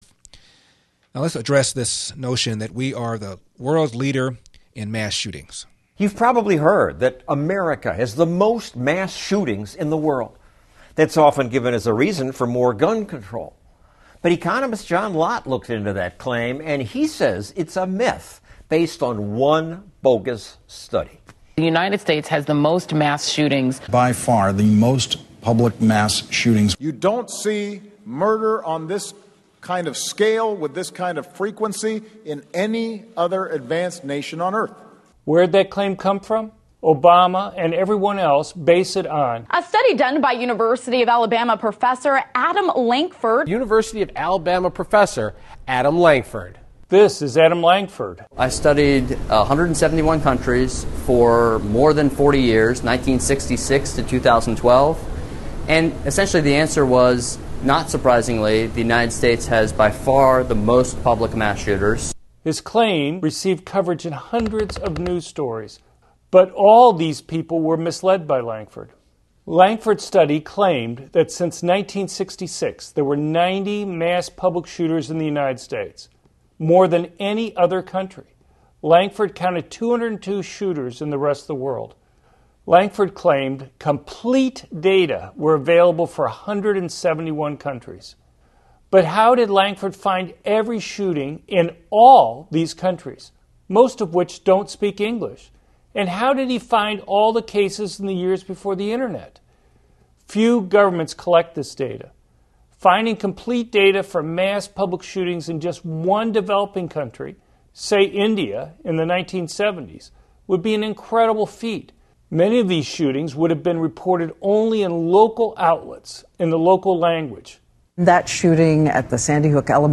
Larry Elder played the audio from our new video on his national radio show.